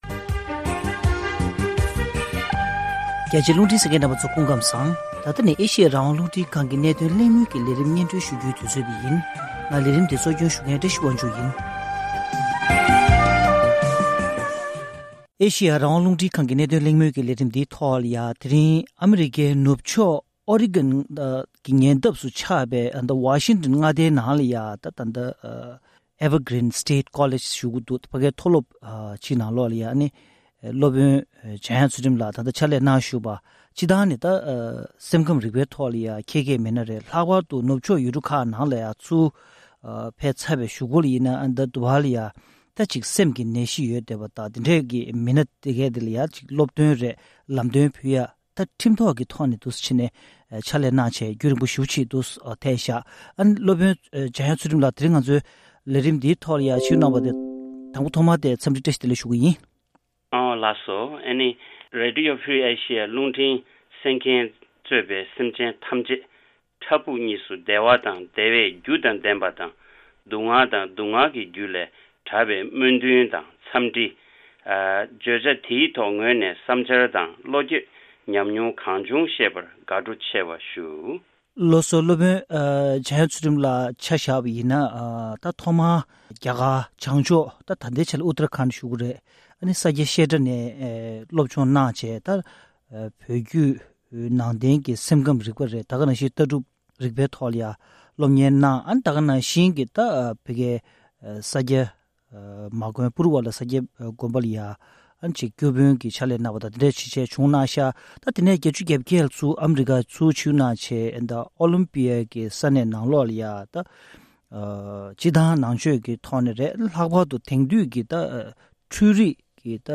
གནད་དོན་གླེང་མོལ་གྱི་ལས་རིམ